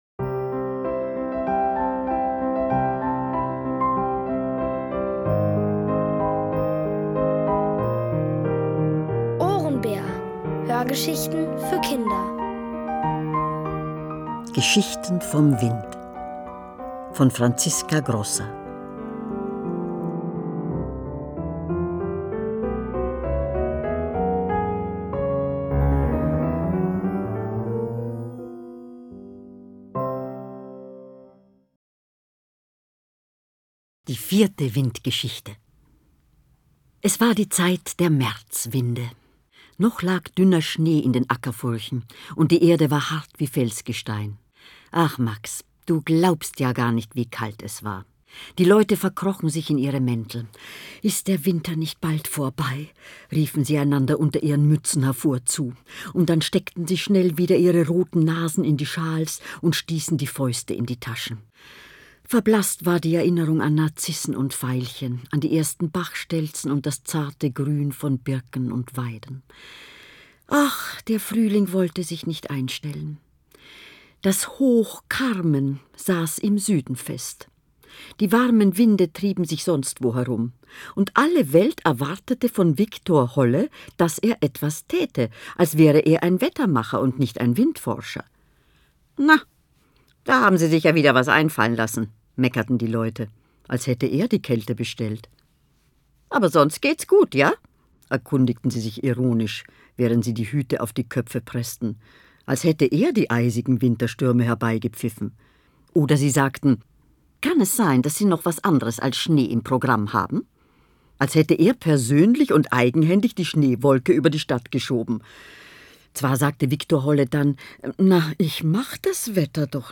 Von Autoren extra für die Reihe geschrieben und von bekannten Schauspielern gelesen.
Es liest: Elfriede Irrall.